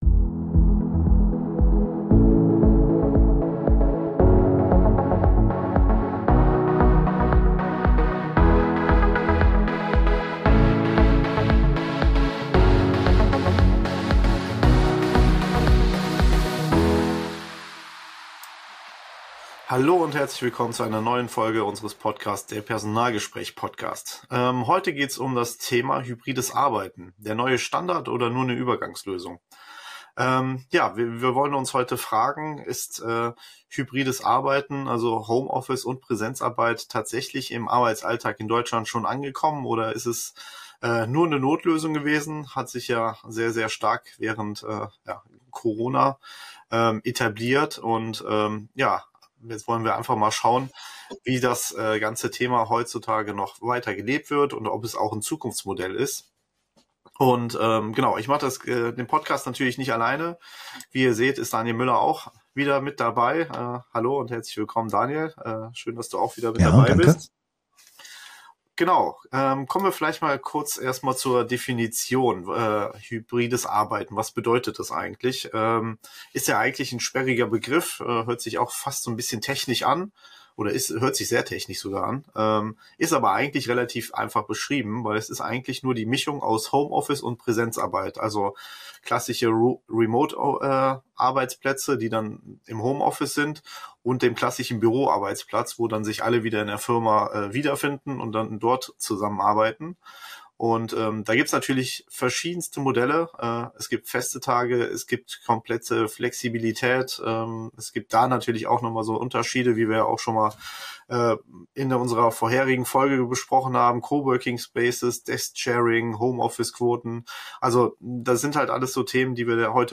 Beschreibung vor 10 Monaten Der Personalgespräch Podcast – Zwei Profis, ein Team.